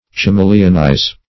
Chameleonize \Cha*me"le*on*ize\